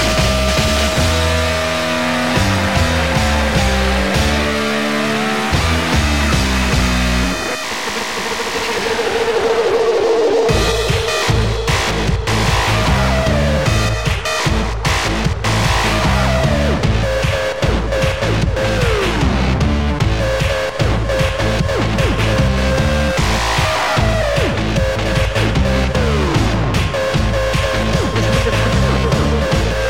TOP >Vinyl >Drum & Bass / Jungle
extended mix